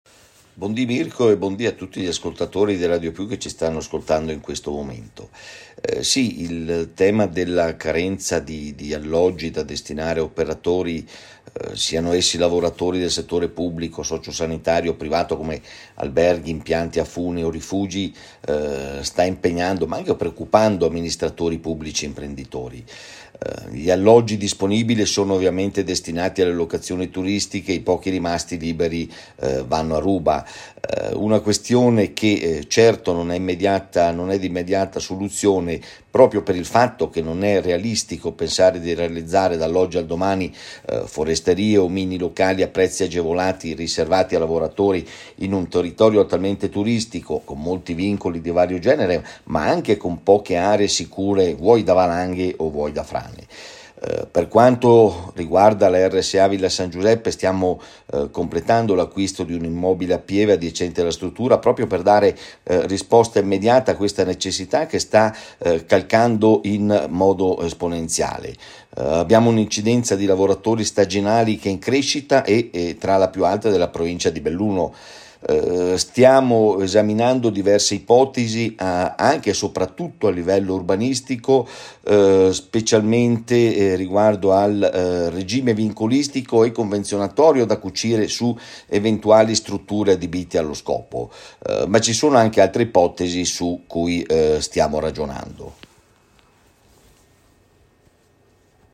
Necessita un’abitazione in affitto a prezzi giusti. Si sta muovendo anche il Comune come ricorda il sindaco Leandro Grones.